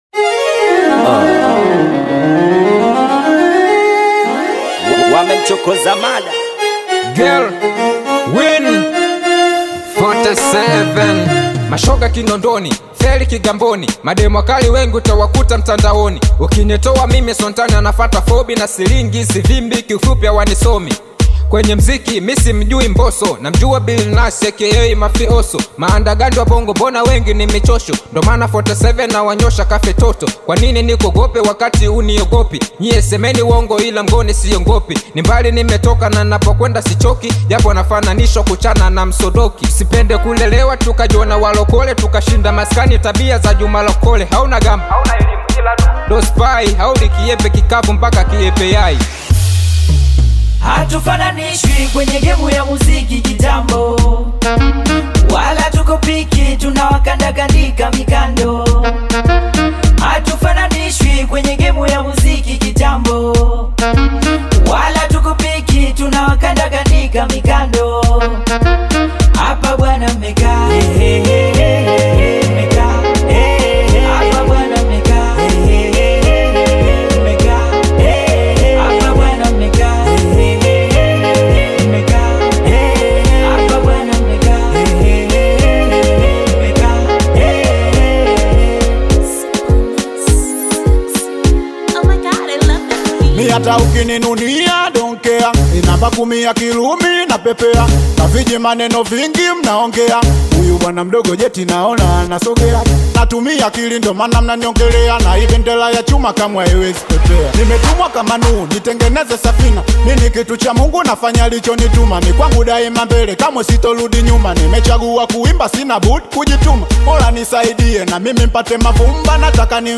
bold Hip-Hop/Afro-fusion single
Genre: Singeli